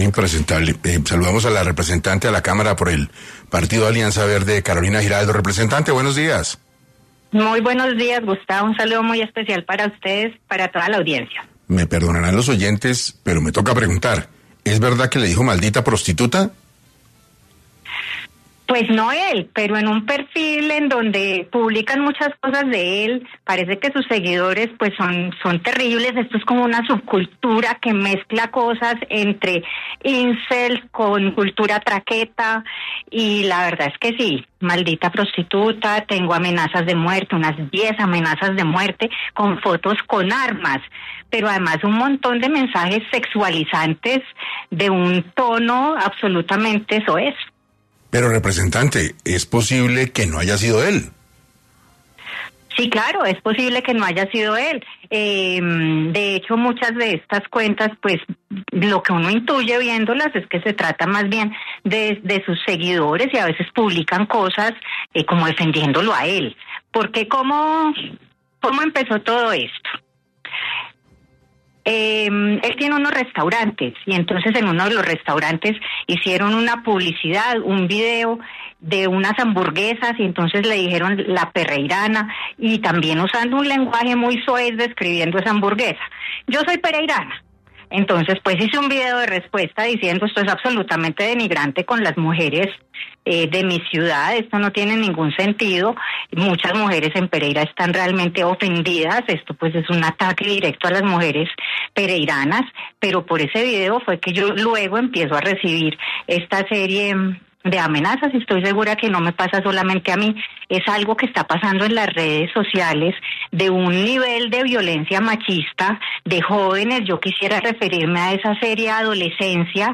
En entrevista para 6AM, la representante Carolina Giraldo, habló sobre las múltiples amenazas que ha recibido por parte de la comunidad del influencer ‘Westcol’, ¿Qué es lo que está pasando?
En entrevista para 6AM, la representante explicó los fuertes hechos que ha tenido que vivir desde entonces y la importancia de regular a los influencers.